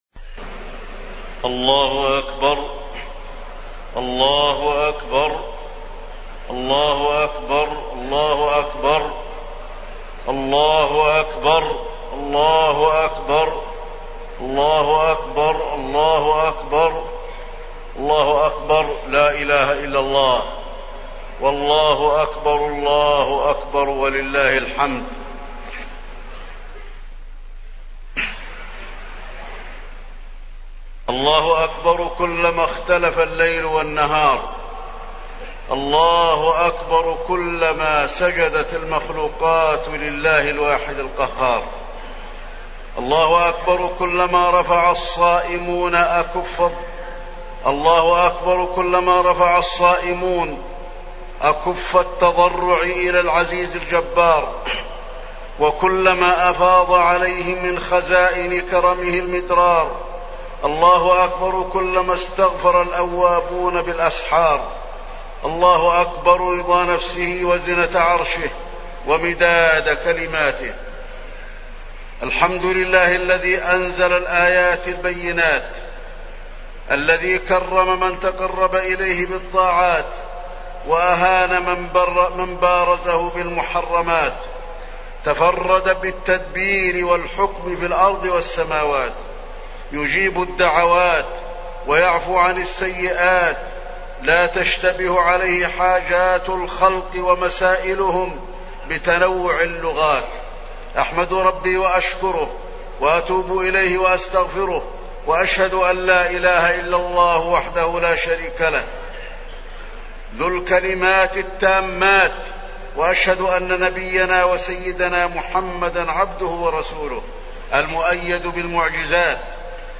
خطبة عيد الفطر - المدينة - الشيخ علي الحذيفي
تاريخ النشر ١ شوال ١٤٢٩ هـ المكان: المسجد النبوي الشيخ: فضيلة الشيخ د. علي بن عبدالرحمن الحذيفي فضيلة الشيخ د. علي بن عبدالرحمن الحذيفي خطبة عيد الفطر - المدينة - الشيخ علي الحذيفي